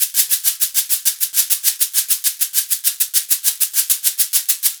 100 SHAK 01.wav